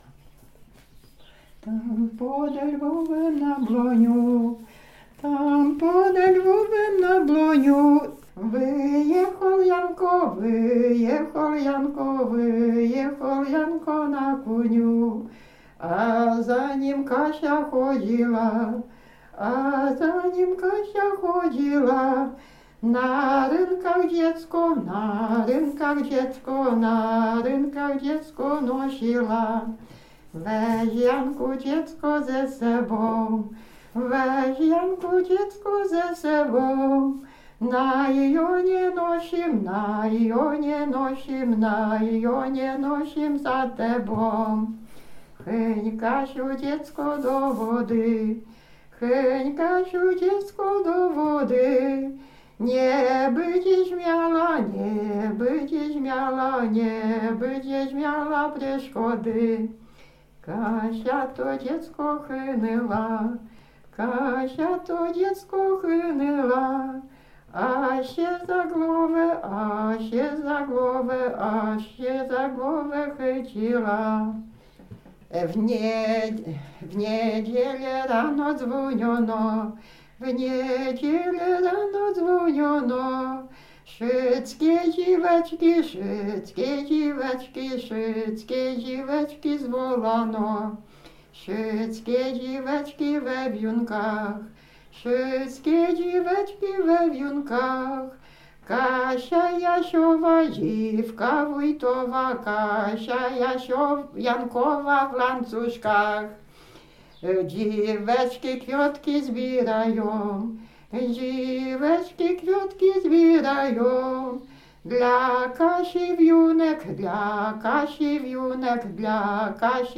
Lubuskie, powiat żagański, gmina Brzeźnica, wieś Wichów
Ballada
Array ballada obyczajowa liryczna